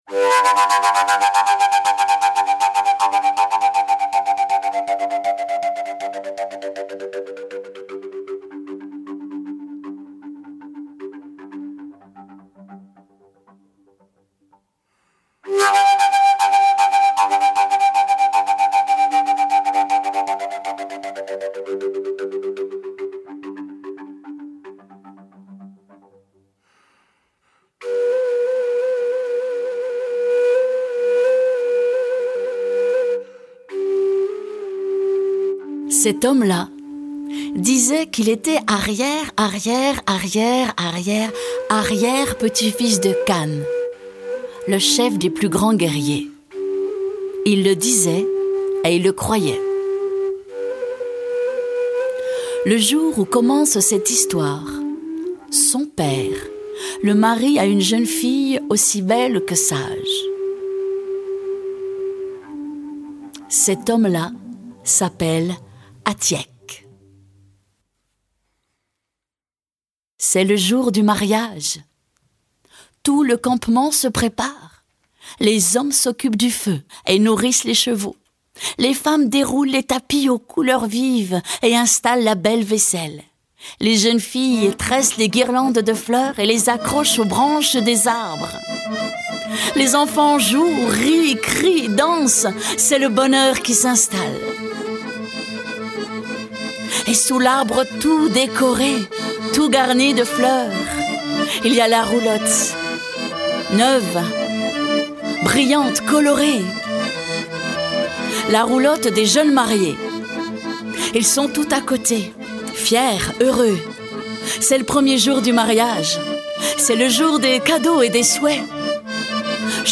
Diffusion distribution ebook et livre audio - Catalogue livres numériques
Avec ses contes venus du coeur des croyances tsiganes et sa musique tressée serrée autour des mots, cette épopée festive nous fait entrevoir l’espace d’un instant, l’univers coloré des Voyageurs.